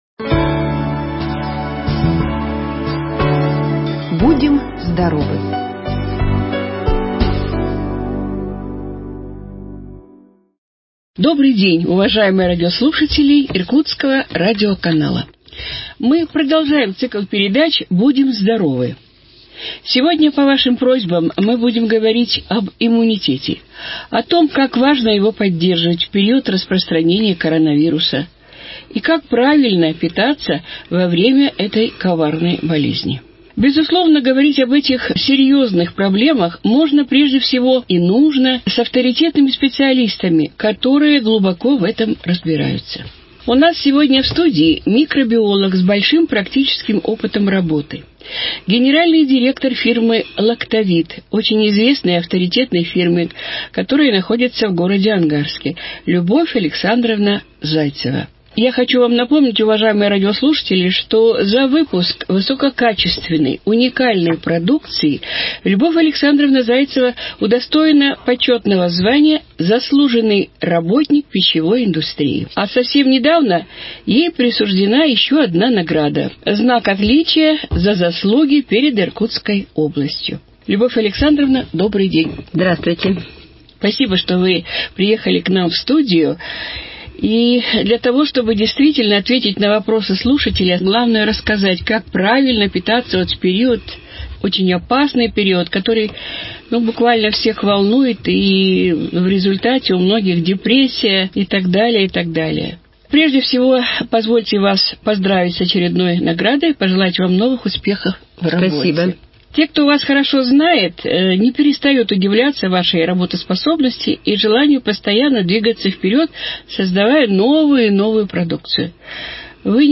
об особенностях питания в период пандемии короновируса и о профилактике других заболеваний беседует с микробиологом